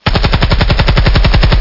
assaultRifle.wav